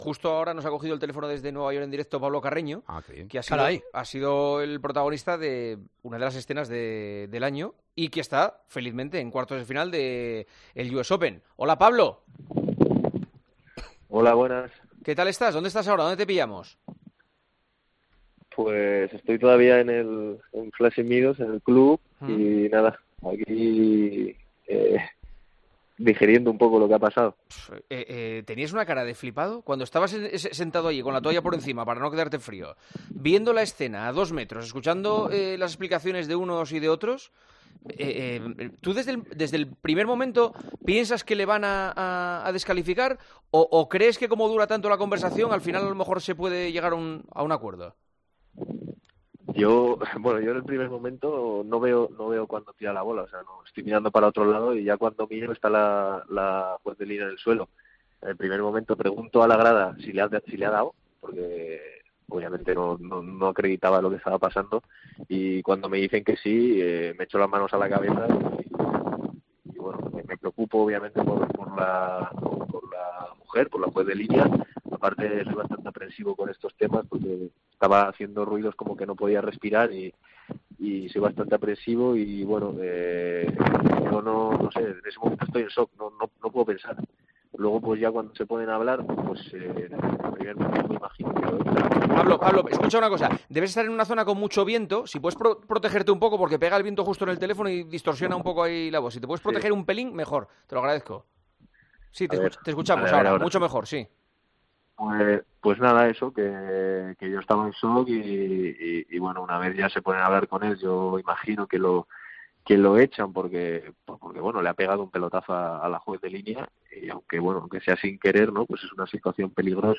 El tenista español estuvo en Tiempo de Juego y habló de cómo vivió todo ese momento: "En el primer momento no veo cuando tira la bola, pero cuando miro, veo que está la jueza en el suelo y pregunto a la grada si le ha tirado la bola, y al decirme que sí, me llevo las manos a la cabeza. Estaba en shock".